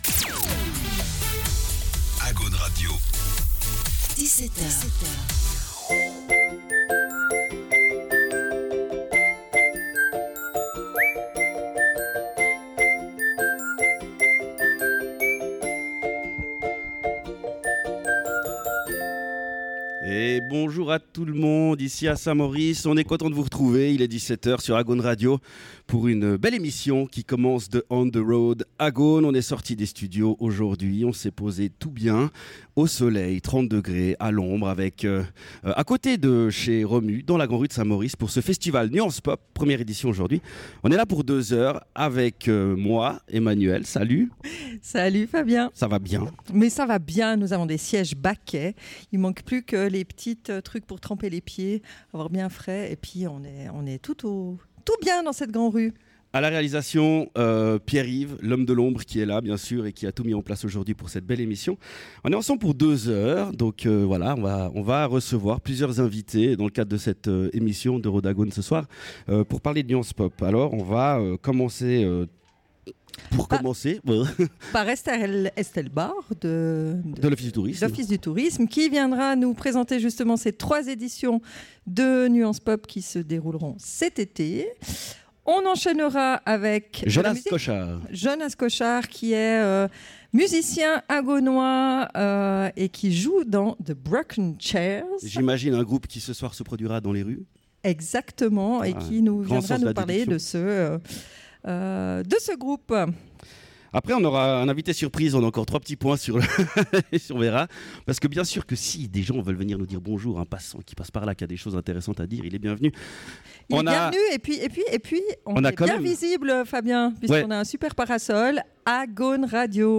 En direct de la Grand-Rue pour la première soirée de Nuances Pop 2025.